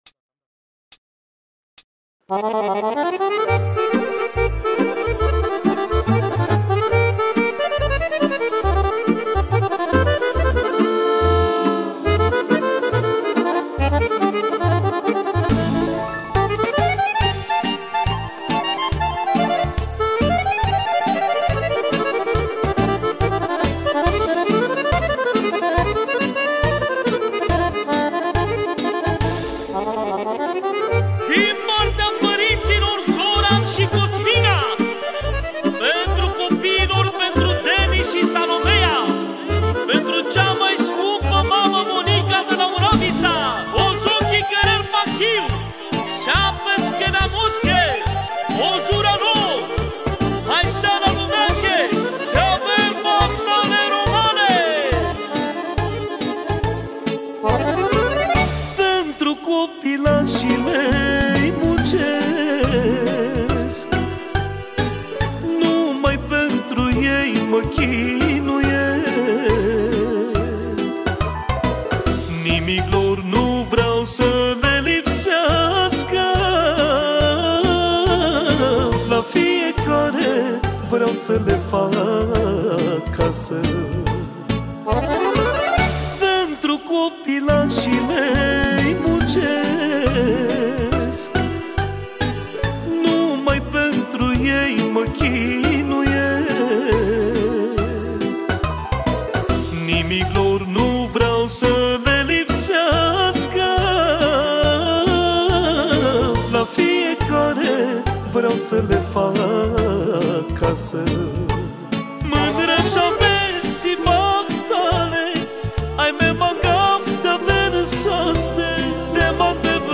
Categoria: Manele New-Live